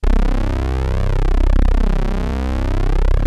PULZEFLANGER.wav